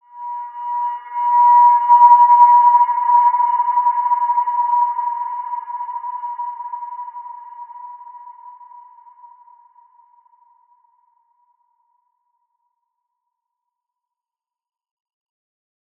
Large-Space-B5-p.wav